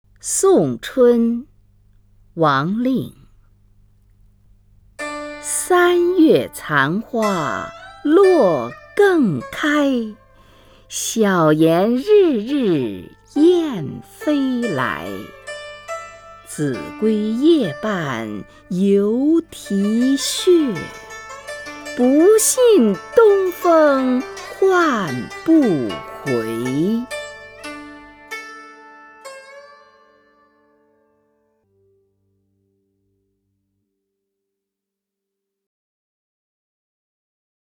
虹云朗诵：《送春》(（北宋）王令) （北宋）王令 名家朗诵欣赏虹云 语文PLUS